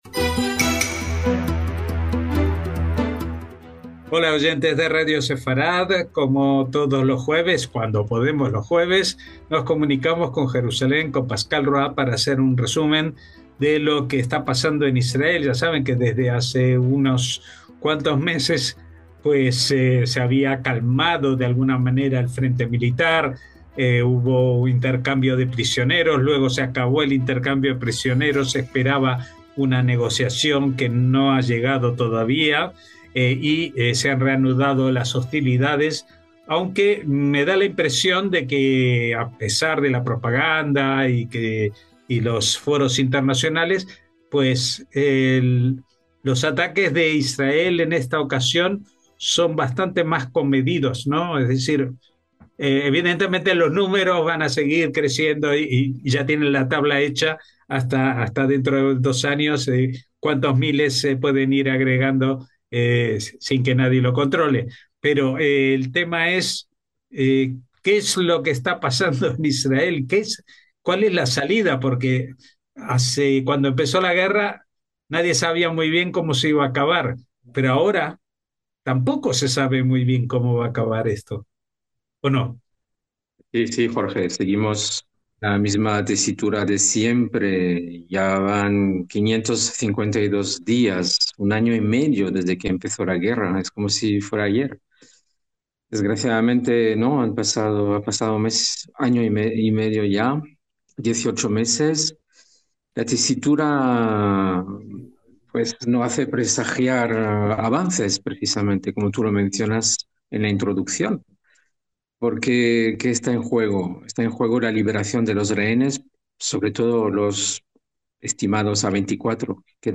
NOTICIAS CON COMENTARIO A DOS - Durante este más de año y medio que dura la guerra de Israel contra Hamás, el aluvión de noticias apenas dejaba paso a comentarios o interpretaciones.